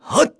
Lusicas-Vox_Attack1_kr.wav